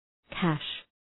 Shkrimi fonetik
{kæʃ}